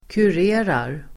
Ladda ner uttalet
Uttal: [kur'e:rar]
kurerar.mp3